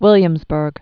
(wĭlyəmz-bûrg)